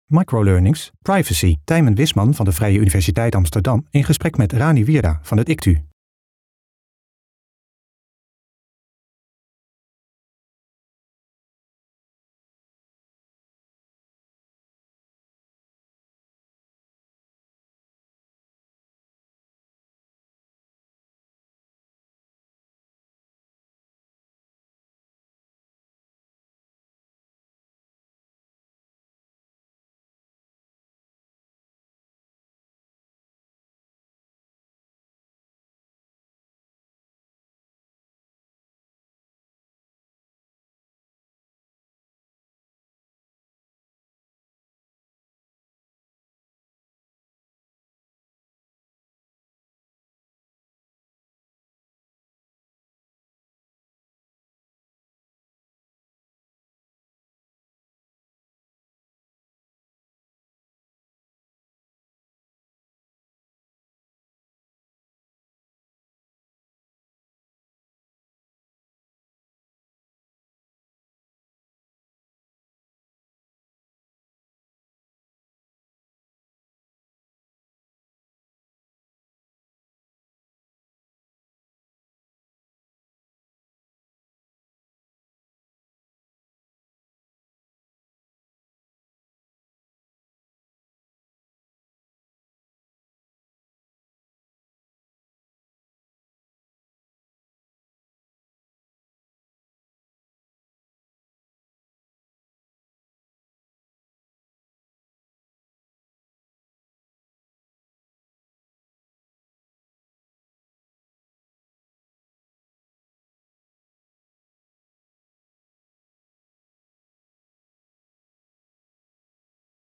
(Intro met abstracte beelden met daaronder een korte begintune.)
(Twee mensen zitten tegenover elkaar in een verder lege kantoorruimte en starten het gesprek: